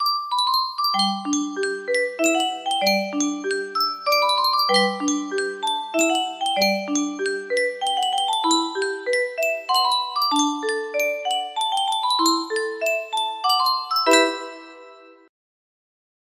Yunsheng Music Box - Rhapsody on a Theme of Paganini Y586 music box melody
Full range 60